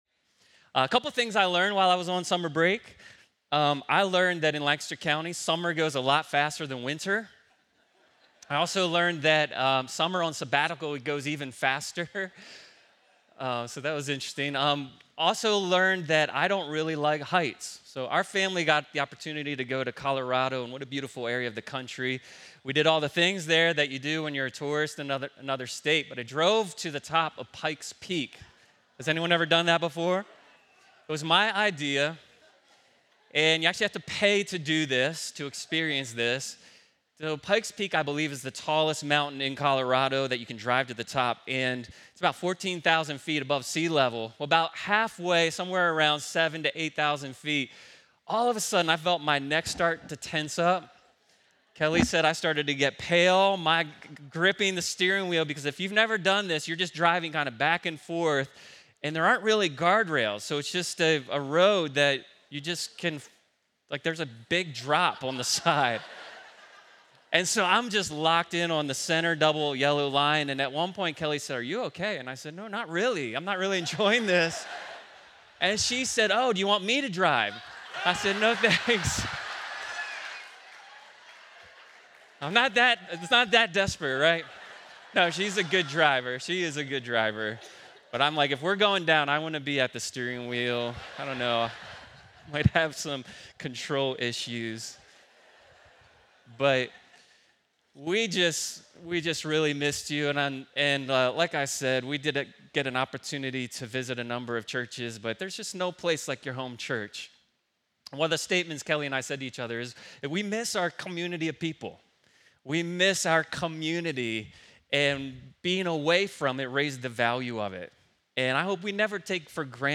Due to our outdoor services At The Tent, there is no video for this week’s service.